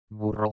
Primo tipo
Si pronunciano chiudendo le labbra.